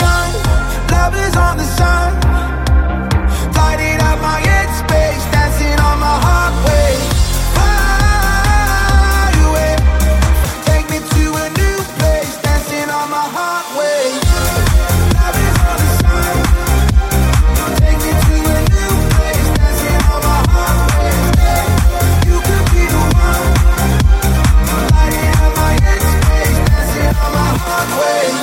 Genere: pop,dance,deep,disco,house.hit